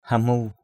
/ha-mu:/